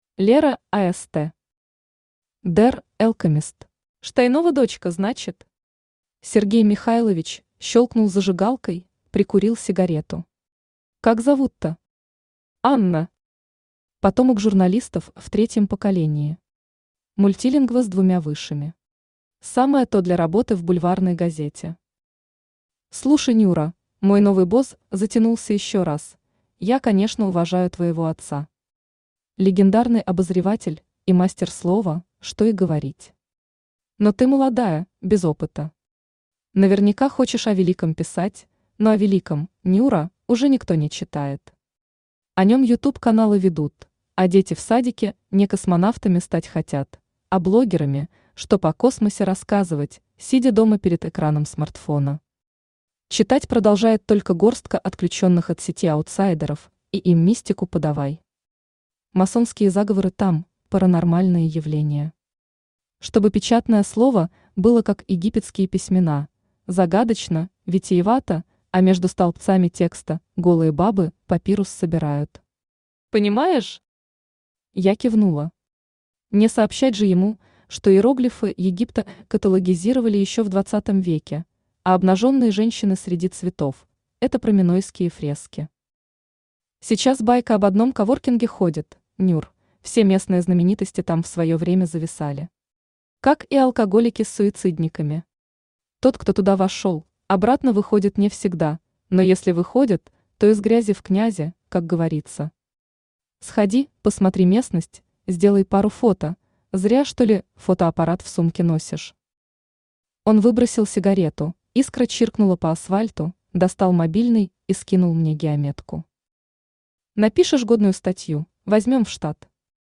Аудиокнига Der Alchimist | Библиотека аудиокниг
Aудиокнига Der Alchimist Автор Лера Аст Читает аудиокнигу Авточтец ЛитРес.